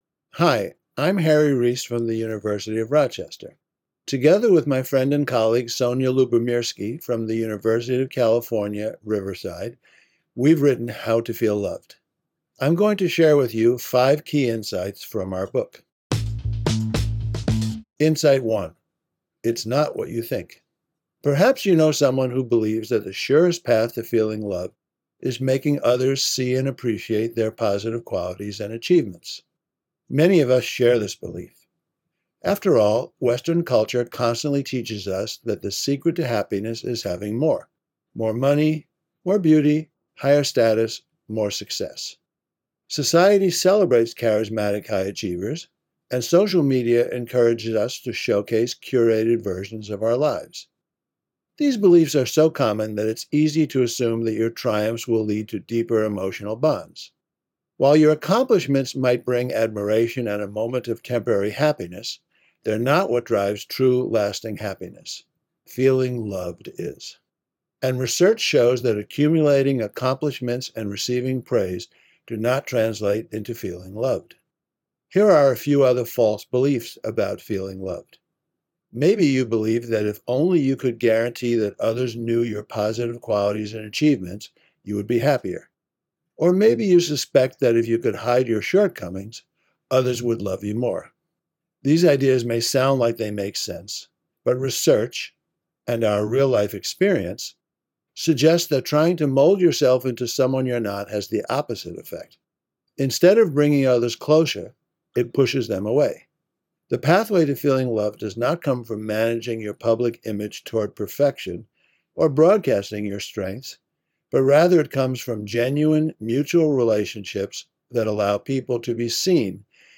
Making it possible to be truly known is what allows love to be felt. Listen to the audio version of this Book Bite